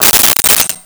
Silverware Movement 03
Silverware Movement 03.wav